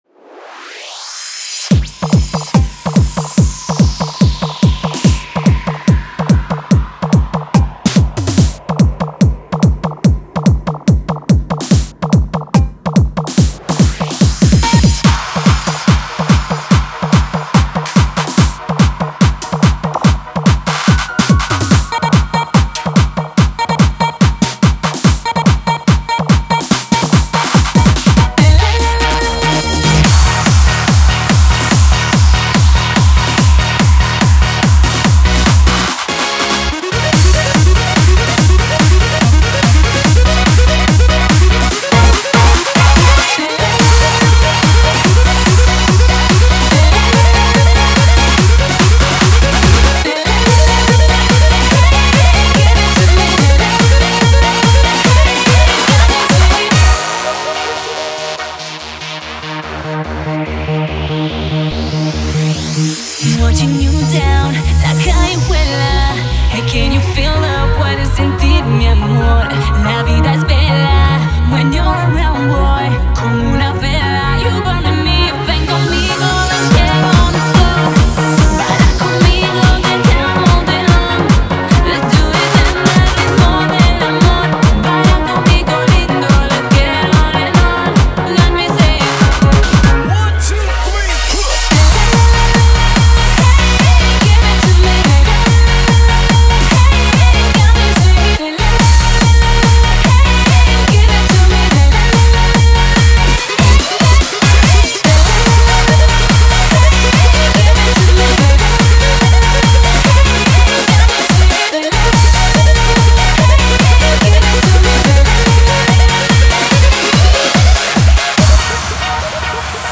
Hands Up Will Never Die <3